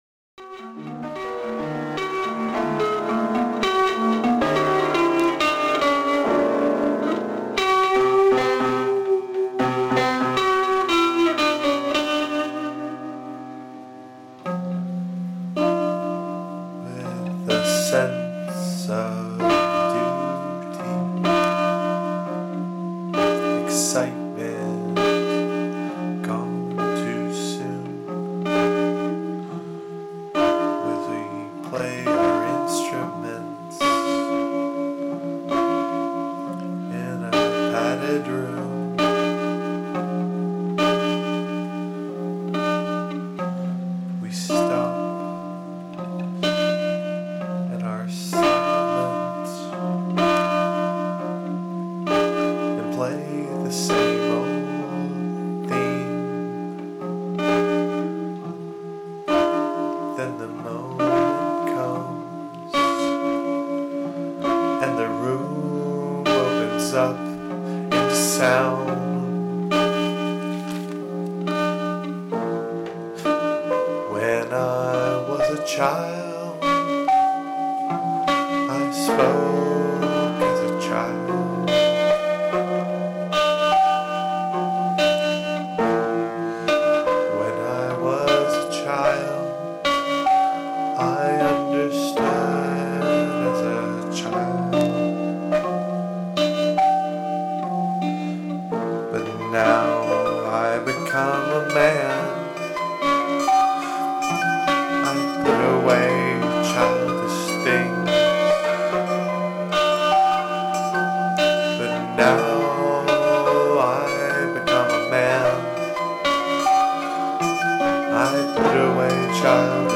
What follows is a rough draft of something we talked about doing for a while: composing with bits extracted from free improvisation sessions.
To add some texture, I doubled some of the loops with harmonic or subharmonic multiples. Then some lyrics, but no text until I can work on them a bit.